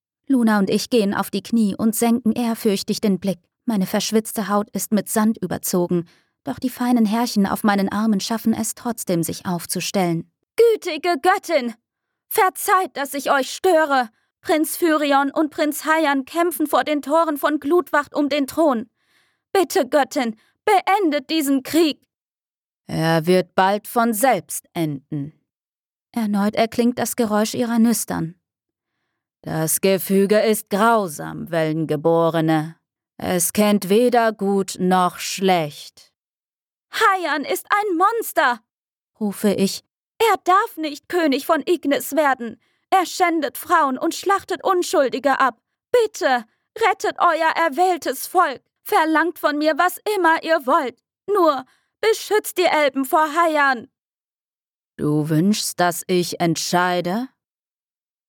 Stimmfarbe: jung, lebendig, gefühlvoll